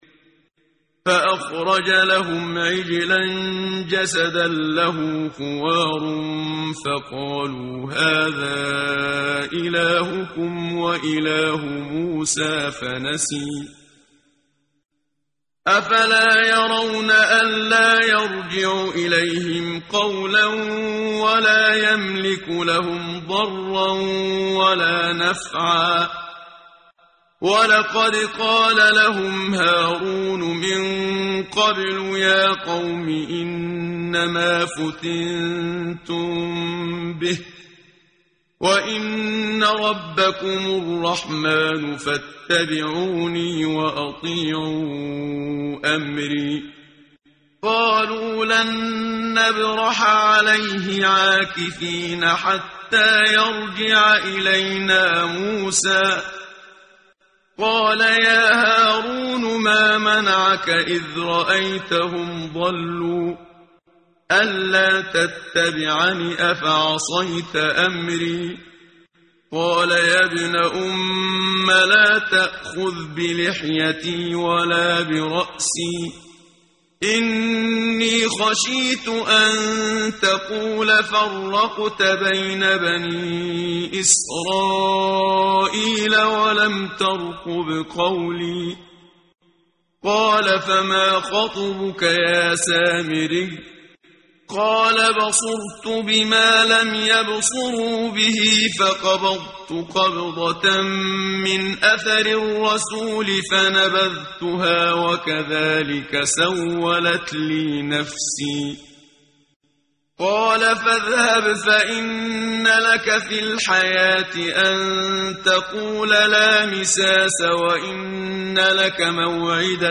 قرائت قرآن کریم ، صفحه 318 ، سوره مبارکه طه آیه 88 تا 98 با صدای استاد صدیق منشاوی.